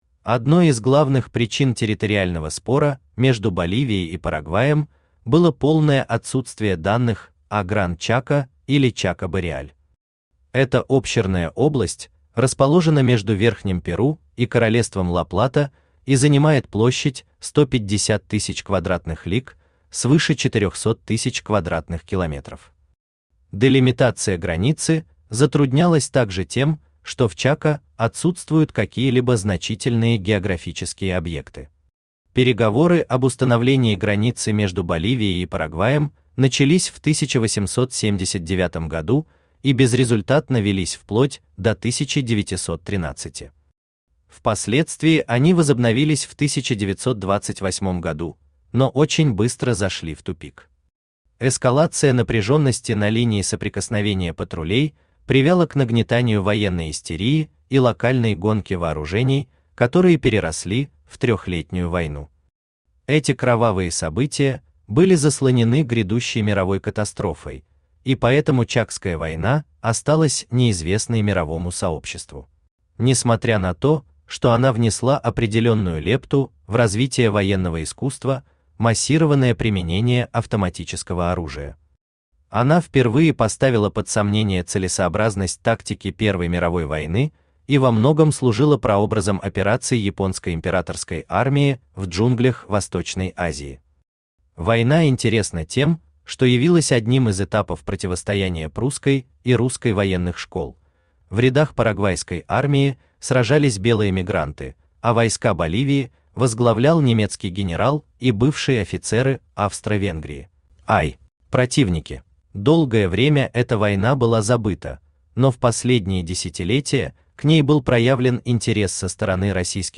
Том I Автор Олег Евгеньевич Царьков Читает аудиокнигу Авточтец ЛитРес.